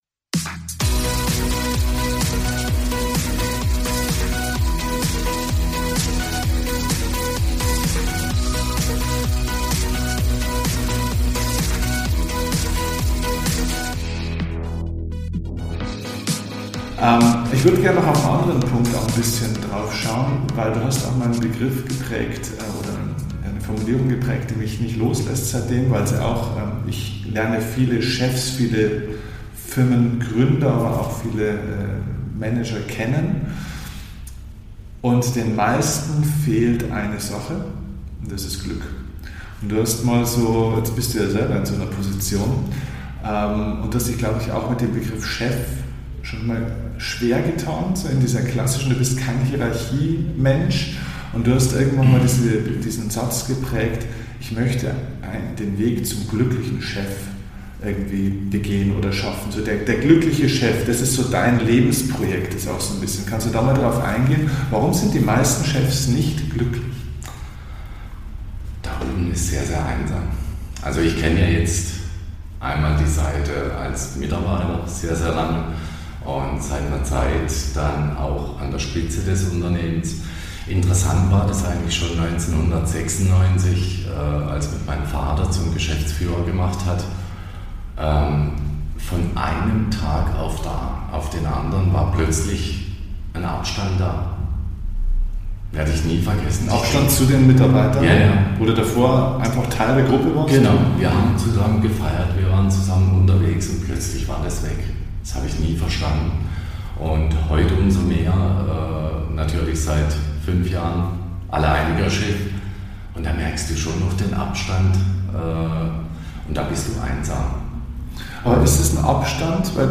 Der glückliche Chef – Unternehmertum der Zukunft – Interview